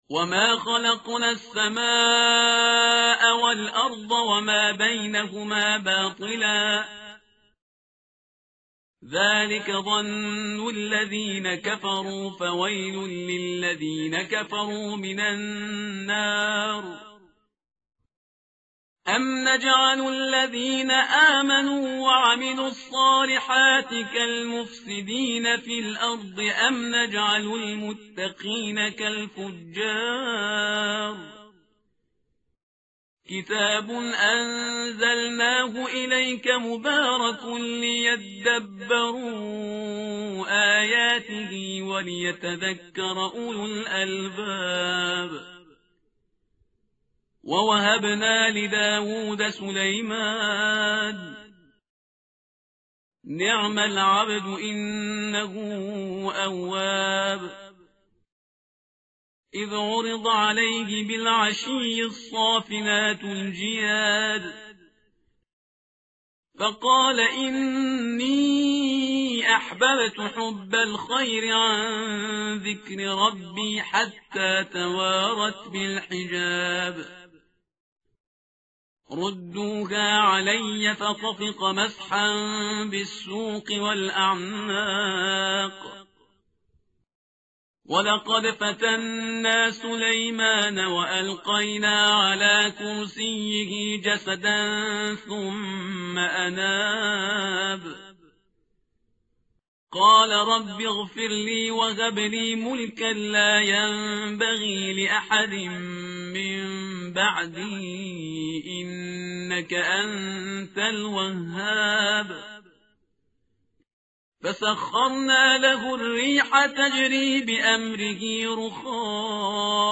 متن قرآن همراه باتلاوت قرآن و ترجمه
tartil_parhizgar_page_455.mp3